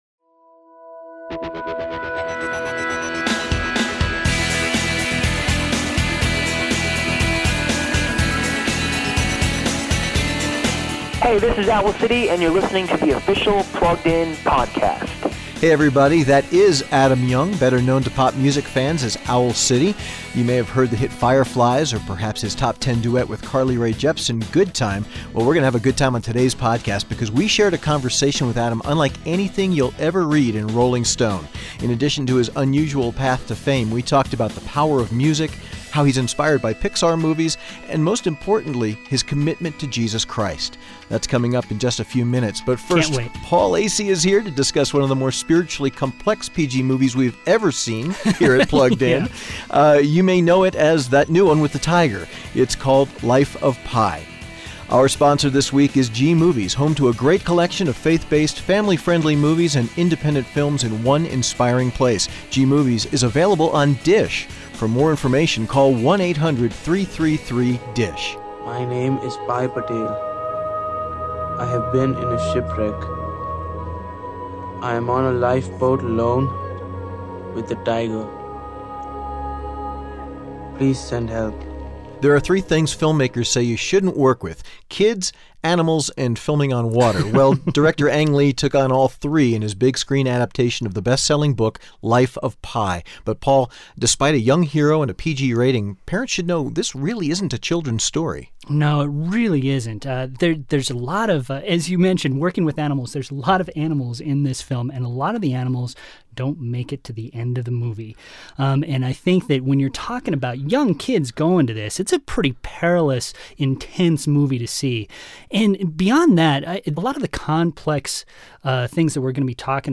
We talked about that recently. Here are just a few excerpts from our conversation on this week’sOfficial Plugged In Podcast—plus moments from the interview that don’t appear on the show: